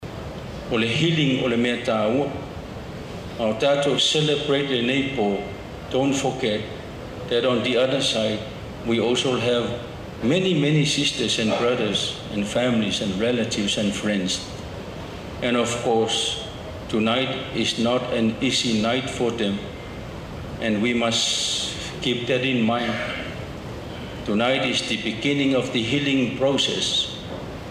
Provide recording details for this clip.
It was just after 9 pm, with results still to come in from Tuala-uta Leone and Nu’uuli , that the mood turned to one of celebration at the Lemanu and Talauega headquarters at Matuu.